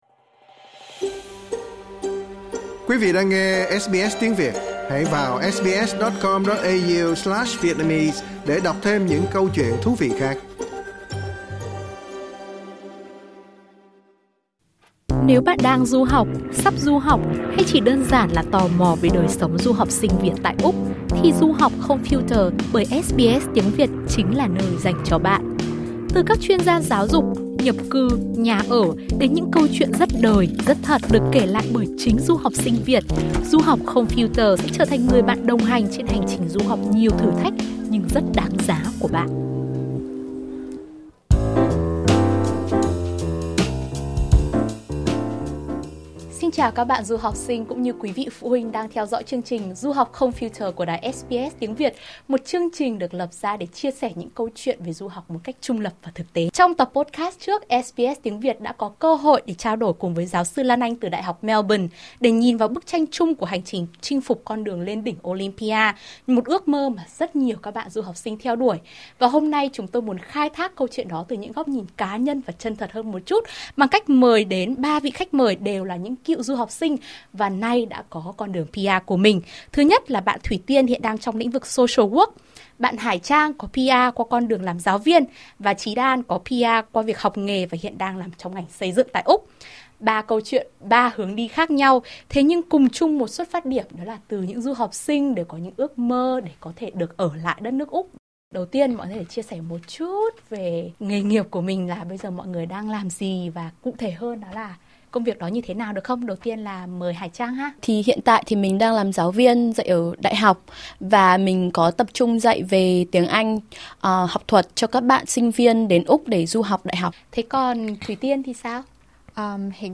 Trò chuyên cùng những cựu du học sinh nay đã có cho mình tấm vé skilled visa tại Úc
Ba khách mời – từng là du học sinh tại Úc – cùng nhìn lại hành trình của mình qua nhiều công việc khác nhau, từ những lựa chọn ban đầu về ngành học, đến những điều chỉnh trong quá trình đi làm và sinh sống.